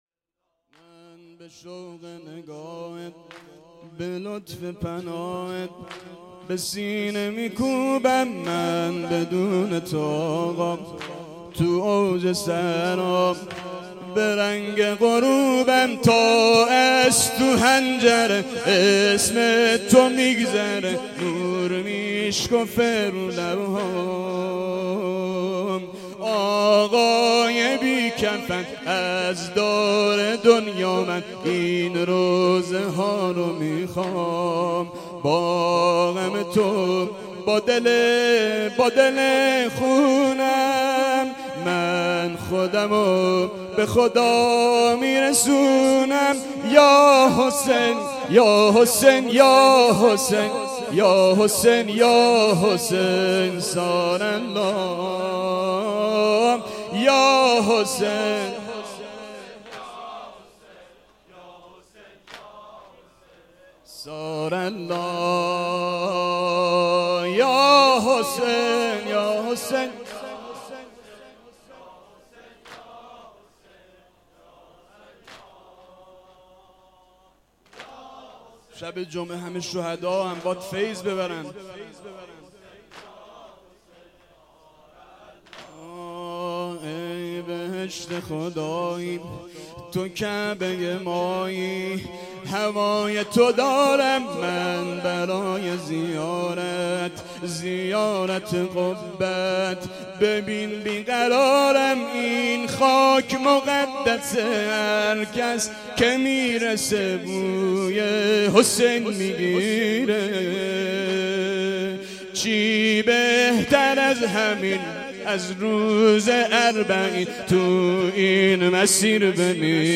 شب ششم محرم 1398